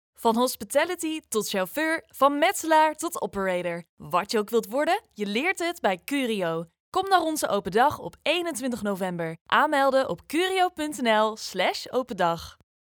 Young, Natural, Playful, Accessible, Friendly
Corporate